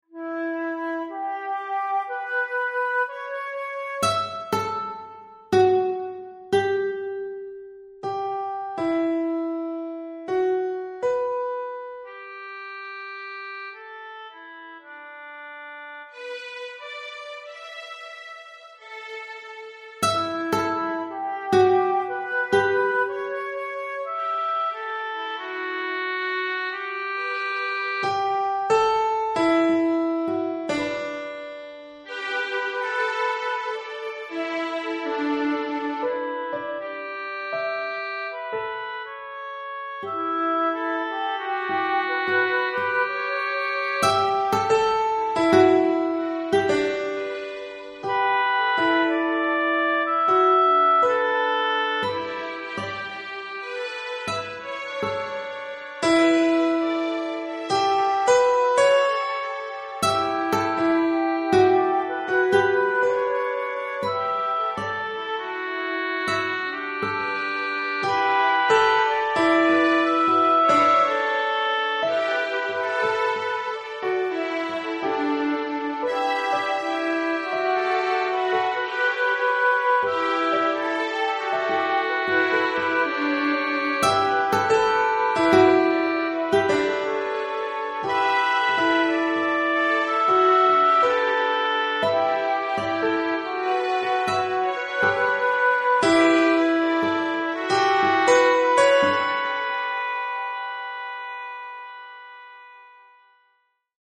Voici un humble exemple mélancolico-répétitif (partition PDF de 75 Ko) :
Au lieu d'illustrer toutes les superpositions possibles de lignes et de colonnes, comme ci-dessus (ce qui donnerait un morceau beaucoup trop long pour son maigre intérêt !), voici un enregistrement (de 818 Ko) commençant par la première ligne seule, puis les deux premières colonnes ensemble, puis les trois premières lignes, les quatre premières colonnes, et enfin toutes les lignes ensemble :